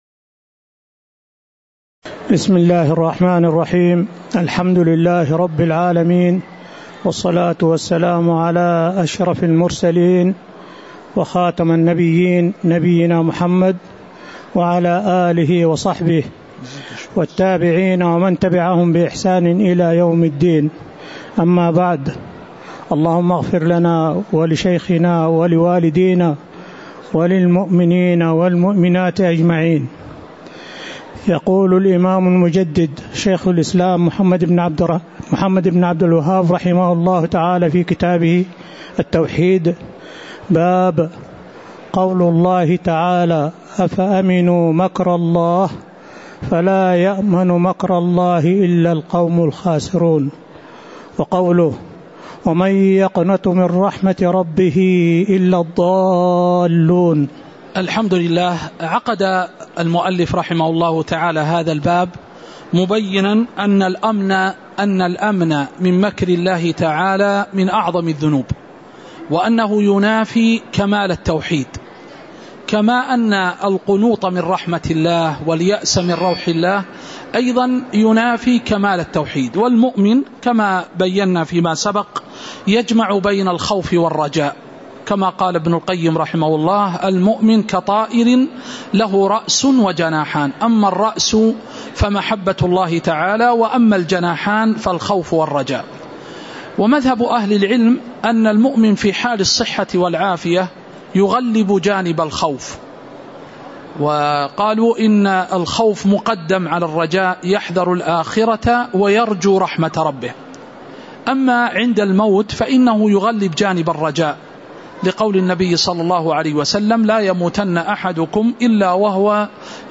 تاريخ النشر ٢٧ شوال ١٤٤٠ هـ المكان: المسجد النبوي الشيخ